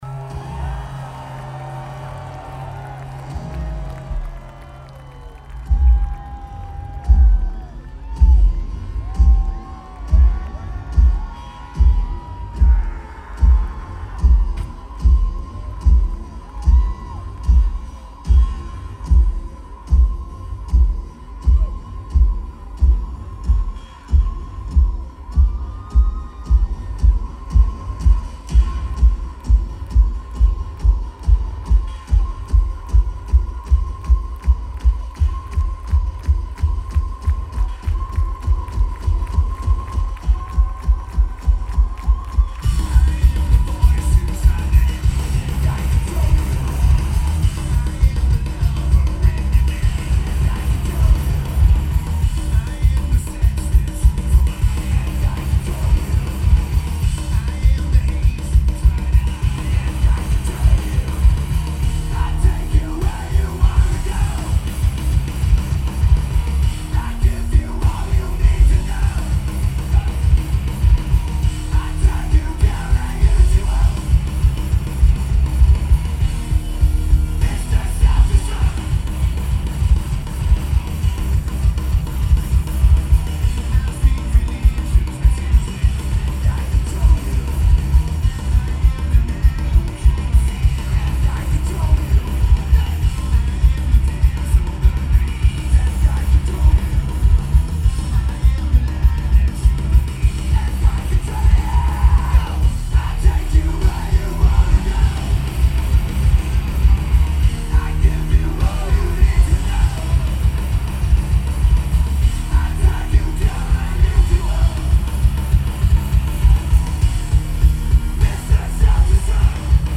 Bonnaroo Music Festival